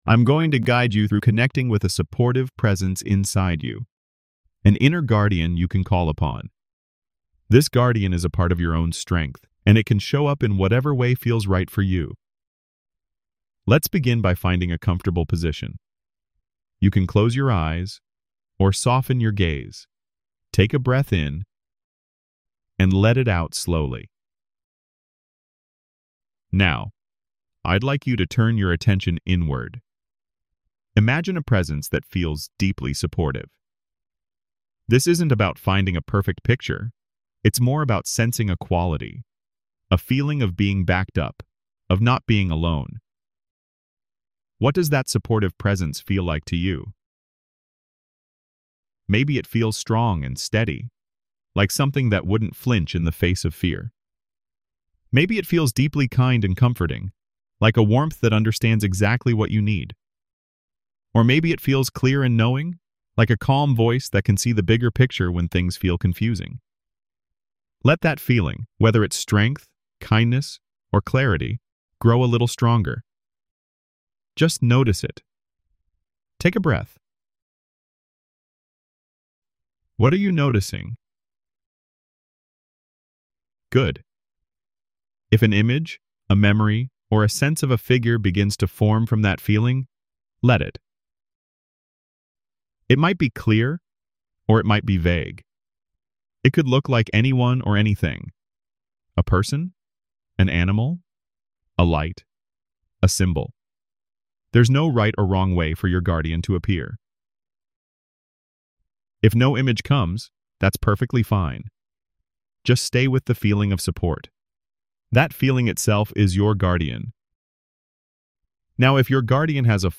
Free audio-guided exercises for EMDR Phase 2 preparation